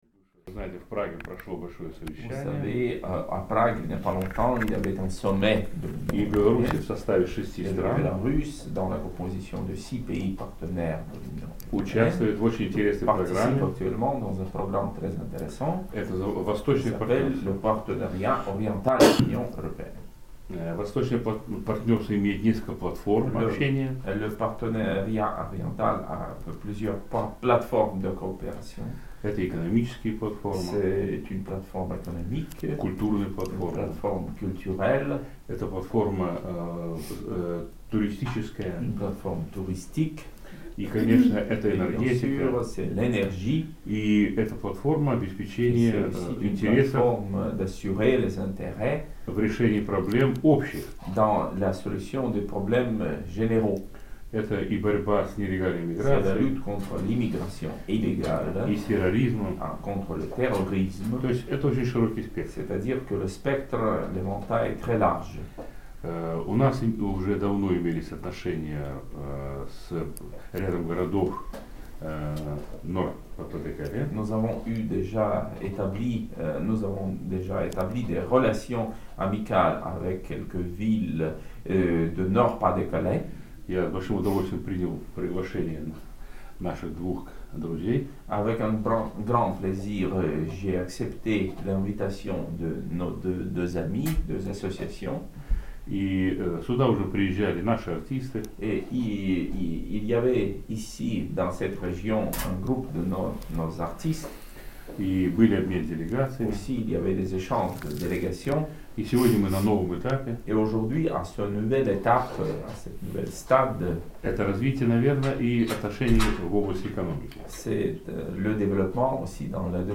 belarus_expose_ambassadeur_Arras_2009.mp3